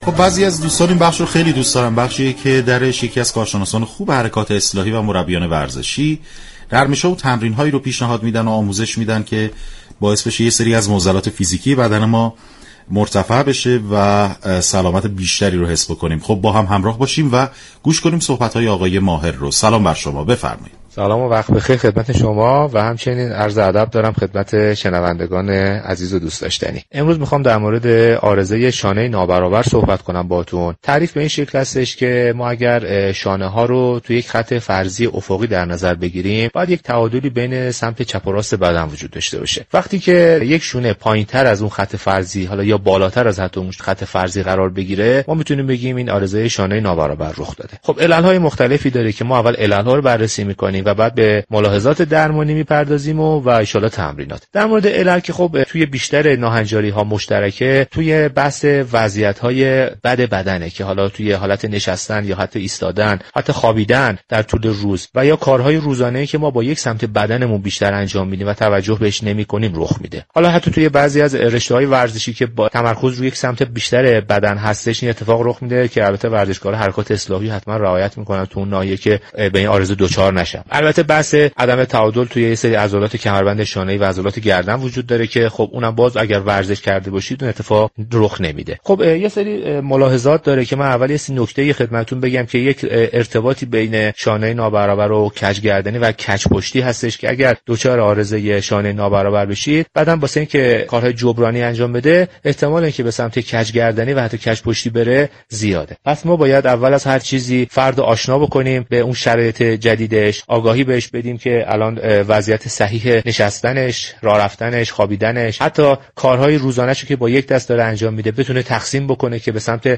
شما می توانید از طریق فایل صوتی پیوست شنونده بخشی از برنامه "گلخونه" رادیو ورزش كه به توضیح درباره چگونگی اجرای تمرین برای بهبود عارضه شانه نابرابر می پردازد؛ باشید.